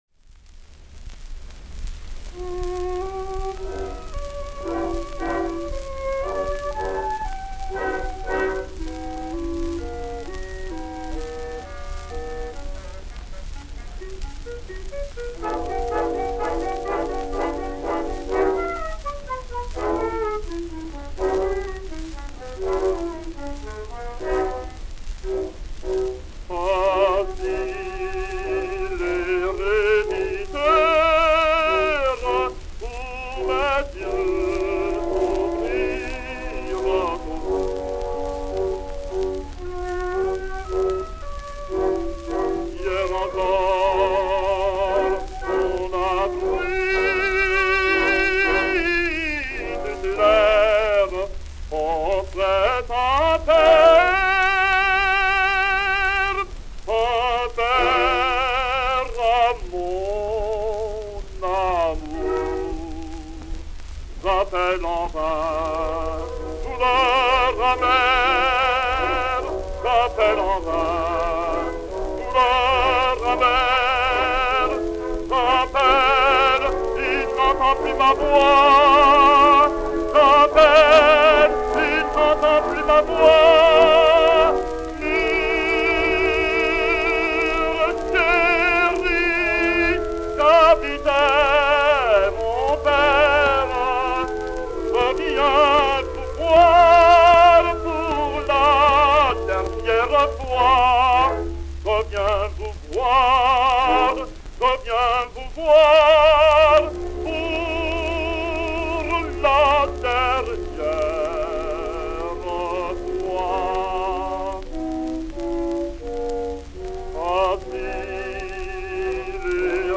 (transposé d'une tierce mineure)